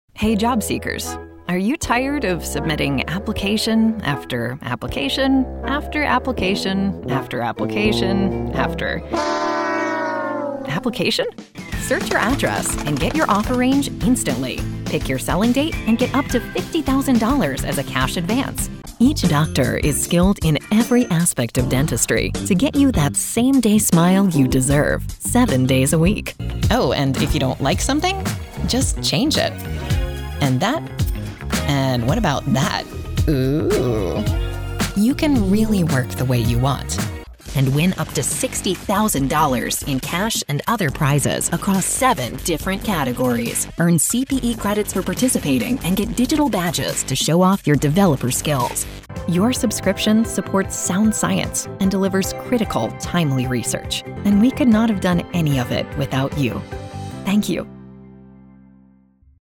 Female
Authoritative, Bright, Corporate, Engaging, Friendly, Natural, Reassuring, Warm
Neutral American English (native)
Audio equipment: Professional home studio, Universal Audio Spark interface